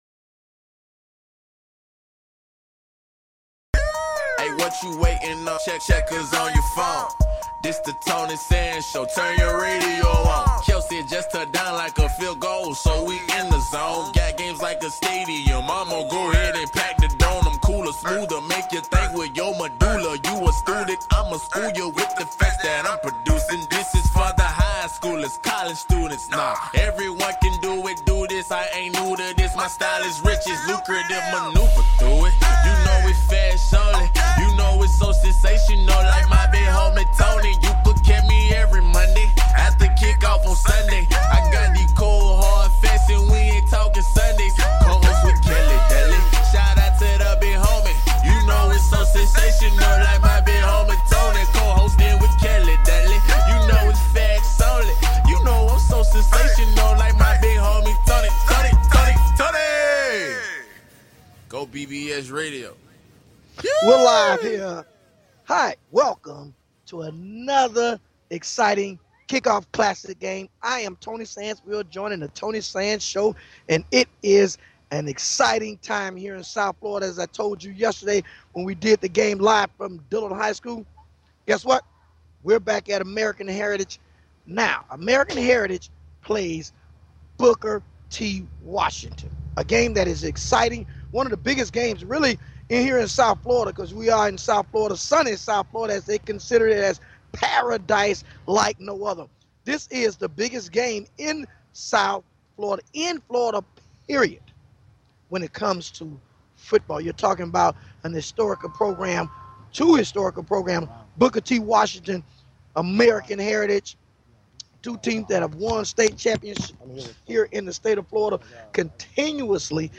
(LIVE ON LOCATION)
Talk Show